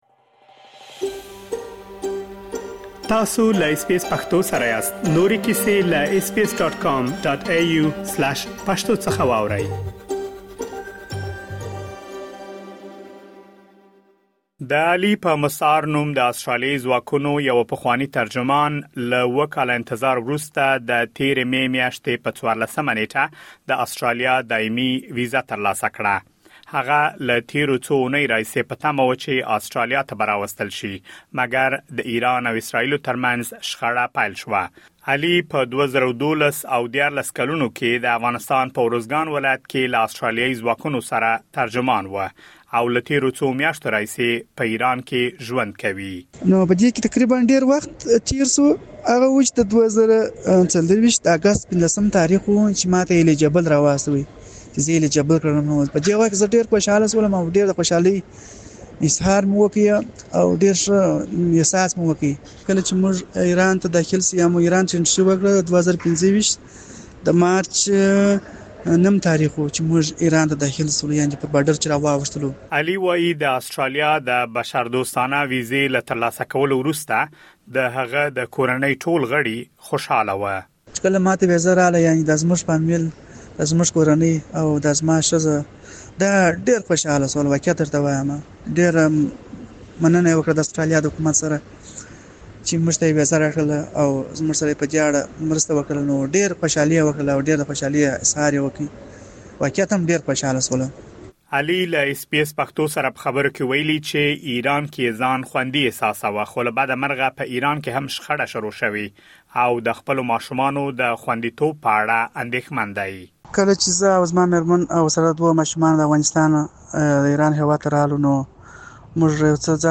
کله چې هغه ته ویزه ورکړل شوه او د سفر چمتووالی یې نیوه، نو د ایران او اسراییلو ترمنځ شخړه پیل شوه. هغه د آسټرالیا له حکومت څخه غواړي چې ژر تر ژره یې آسټرالیا ته انتقال کړي. مهرباني وکړئ لا ډېر معلومات په رپوټ کې واورئ.